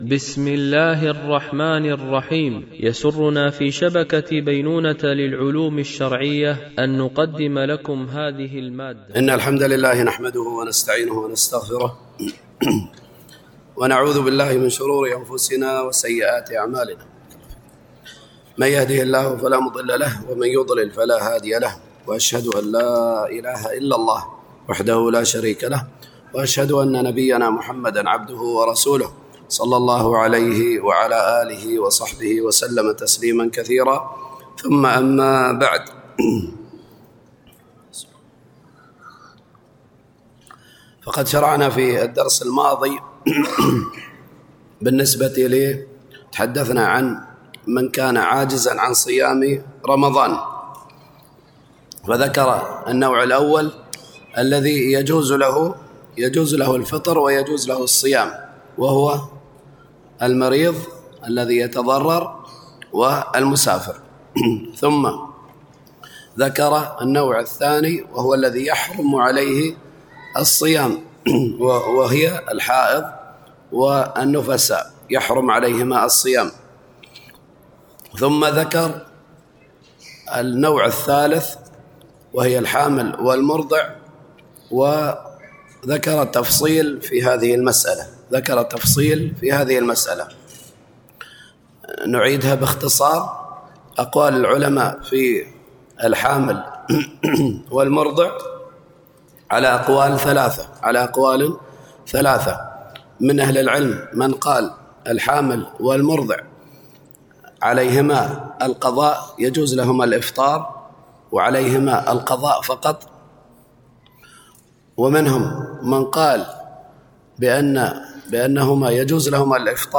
دورة علمية مترجمة للغة الإنجليزية، لمجموعة من المشايخ، بمسجد أم المؤمنين عائشة رضي الله عنها